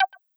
GenericNotification4.wav